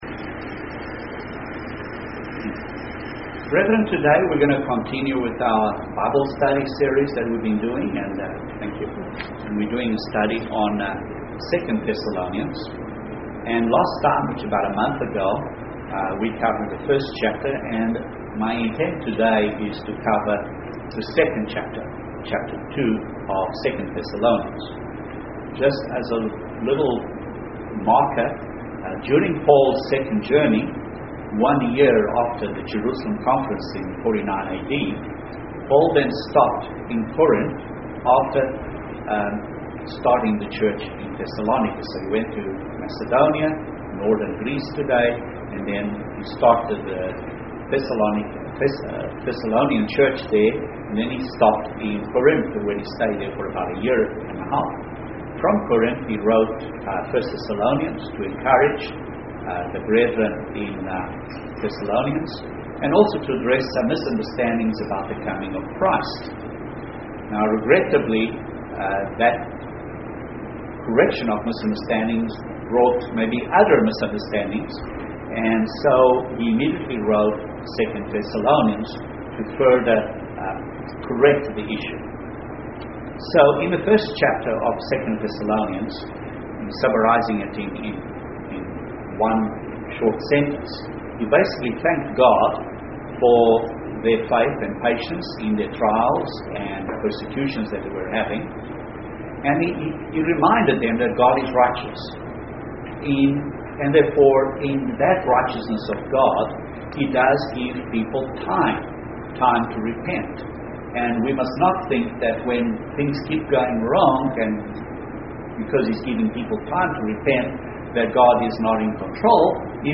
Bible Study - 2 Thessalonians 2